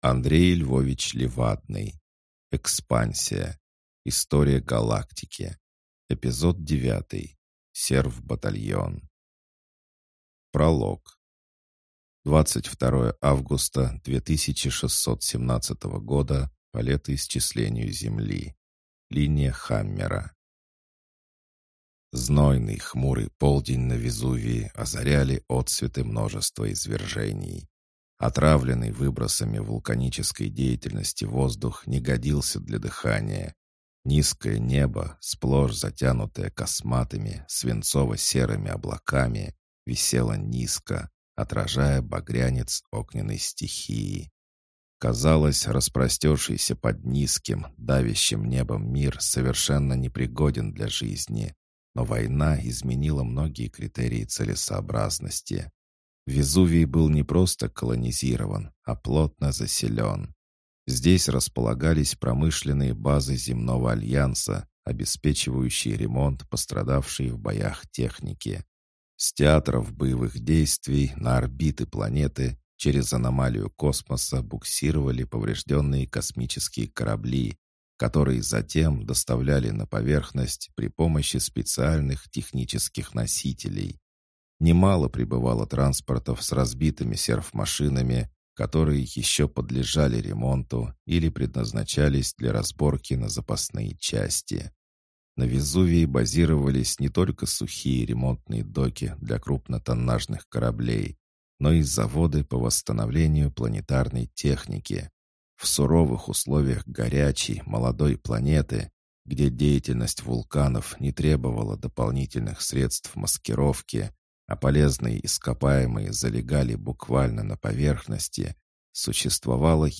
Аудиокнига Серв-батальон | Библиотека аудиокниг